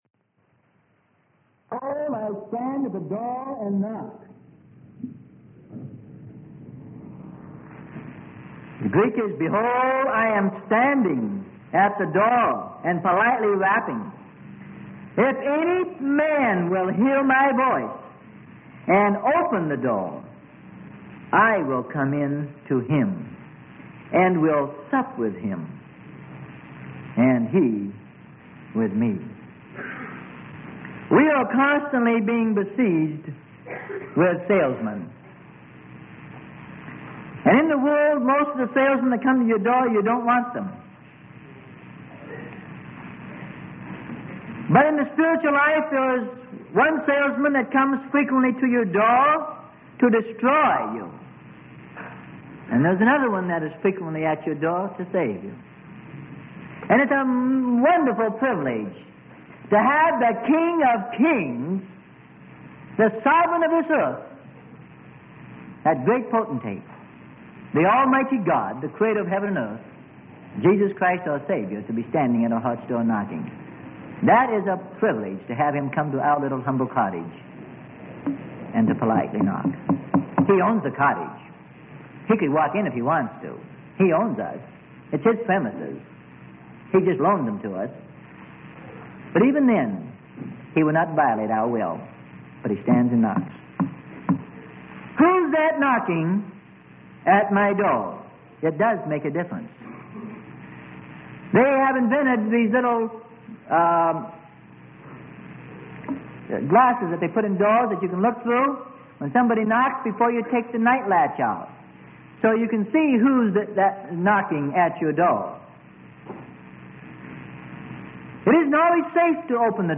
Sermon: God's Doctrine and Satan's Doctrine - Part 7 - Freely Given Online Library